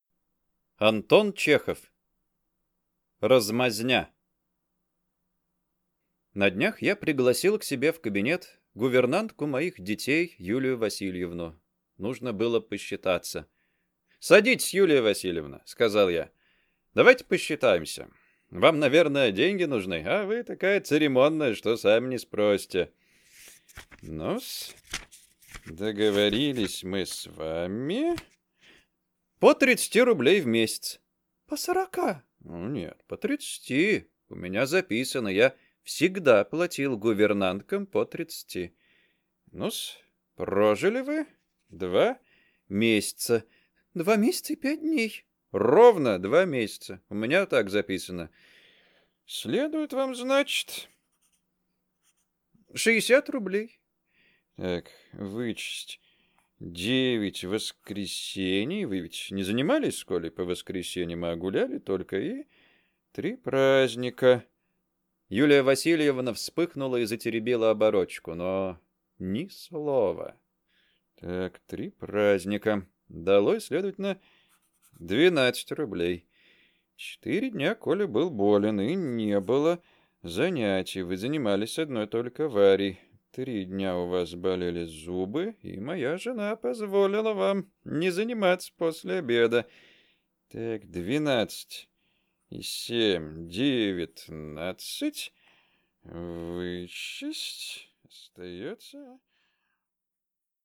Аудиокнига Размазня